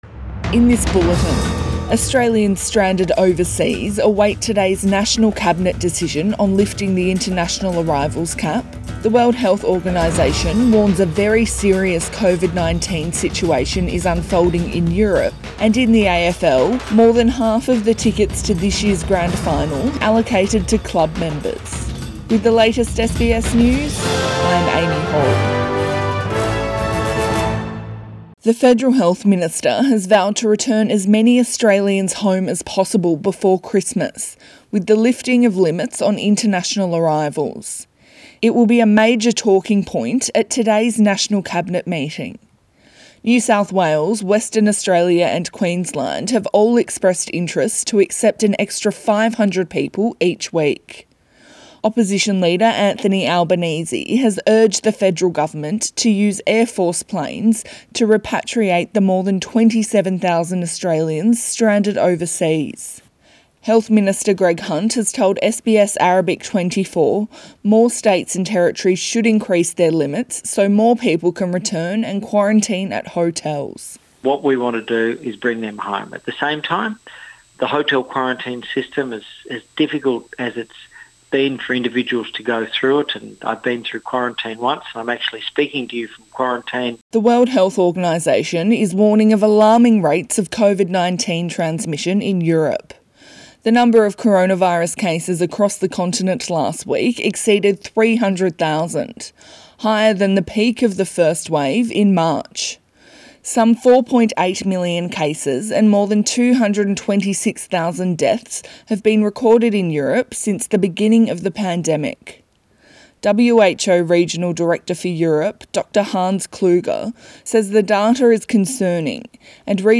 AM bulletin 18 September 2020